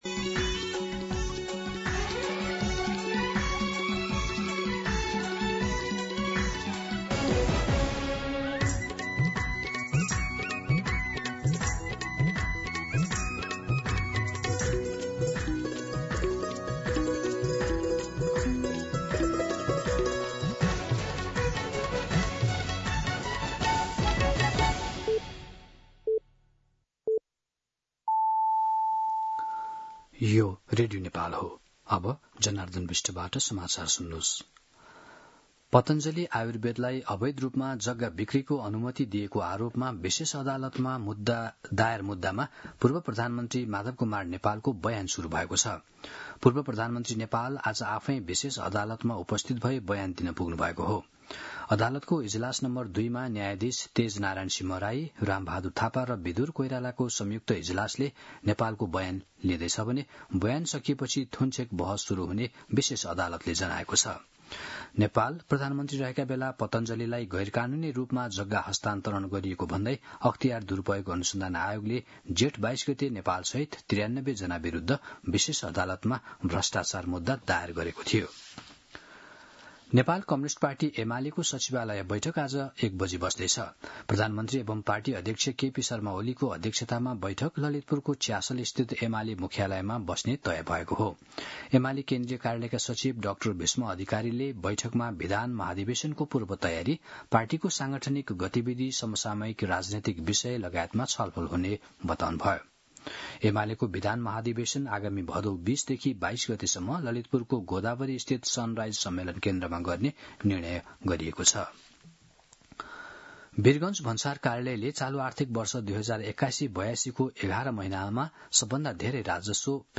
मध्यान्ह १२ बजेको नेपाली समाचार : ११ असार , २०८२